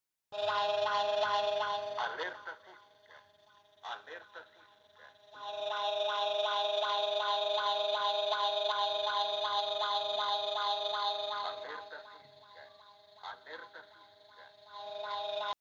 Alertamiento sísmico
Alertamiento-Sismico.mp3